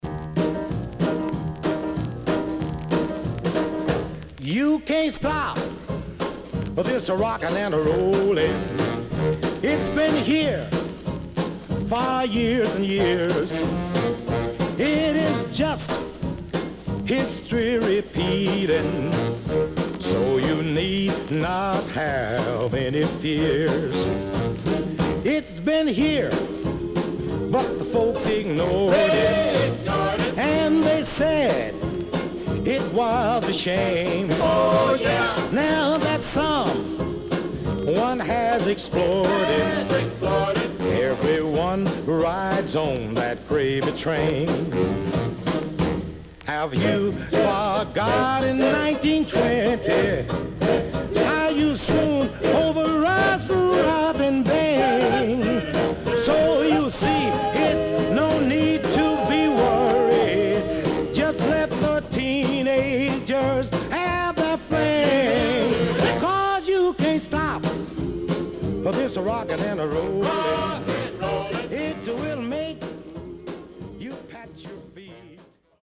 CONDITION SIDE A:VG(OK)〜VG+
SIDE A:所々チリノイズがあり、少しプチノイズ入ります。